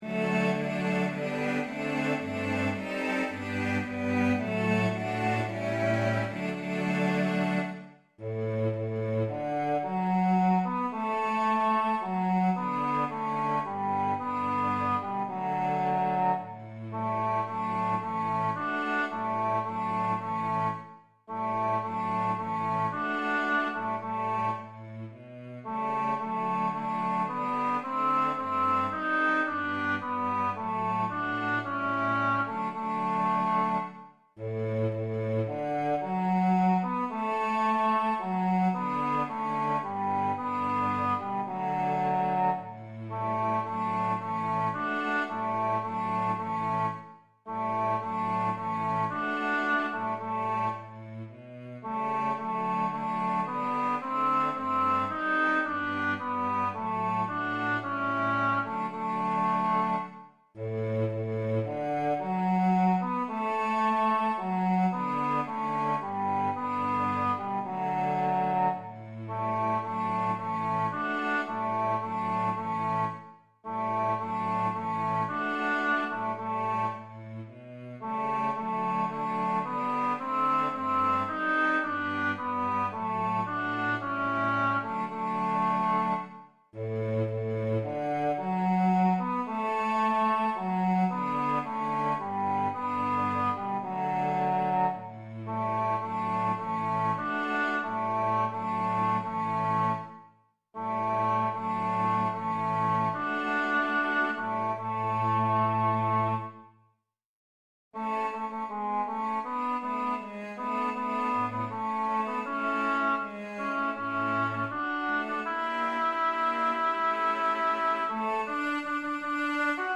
Im Krug zum grünen Kranze Tenor 2 als Mp3
Ein beliebtes Chorstück für Männerchor unter der Überschrift "Einkehr".
im-krug-zum-gruenen-kranze-zoellner-einstudierung-tenor-2.mp3